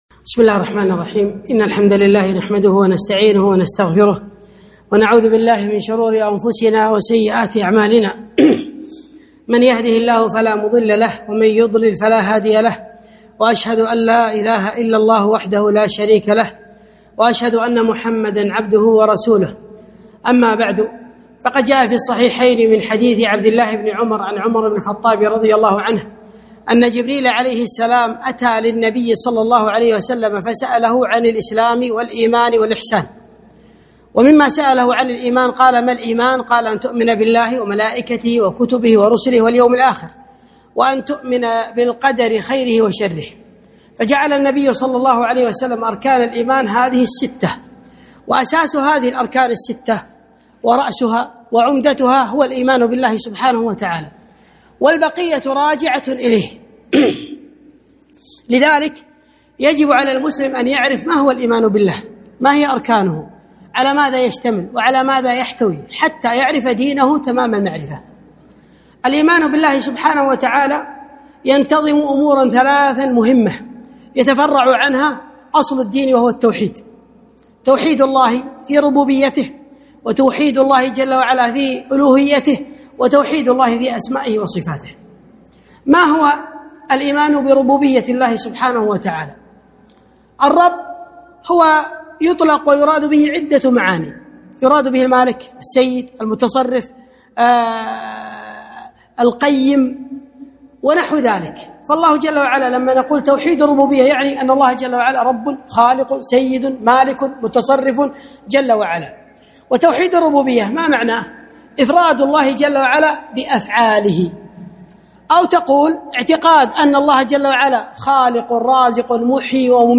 محاضرة - الإيمان بالله عز وجل